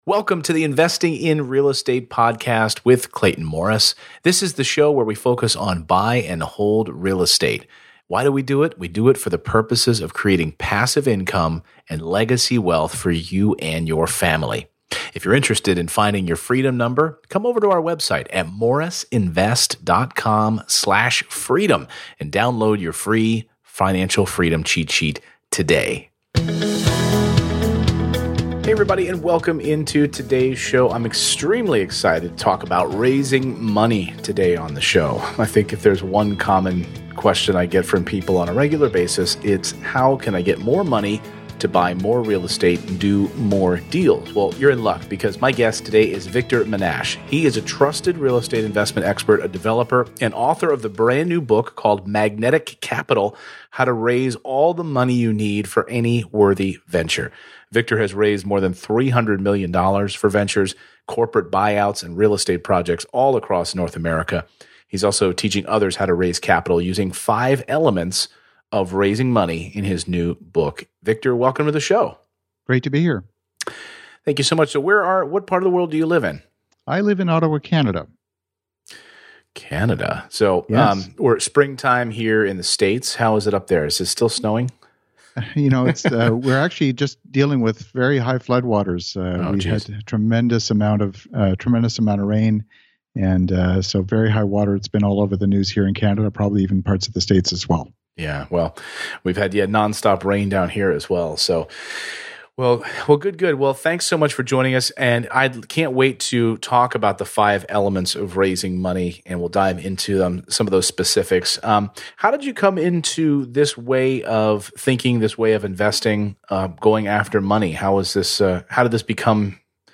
EP179: The 5 Elements of Raising Money for Real Estate - Interview